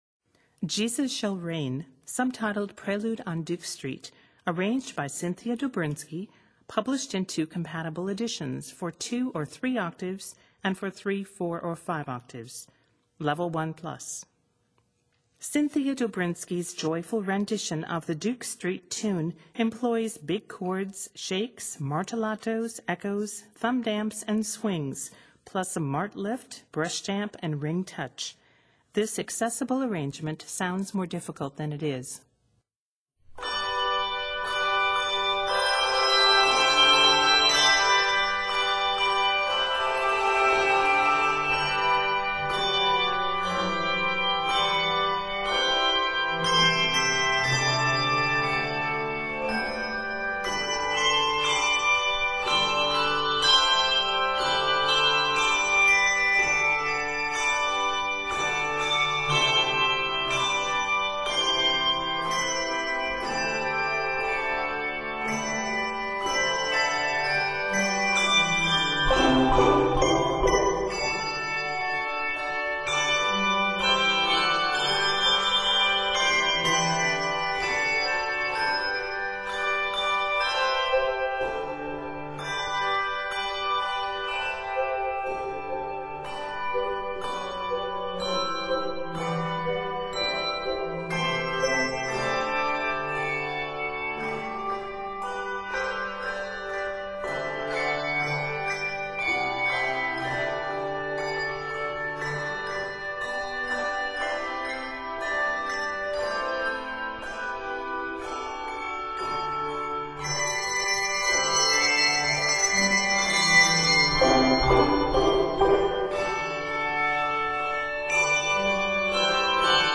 handbell music
This joyful rendition